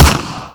thompson_fire_REPLACEME.wav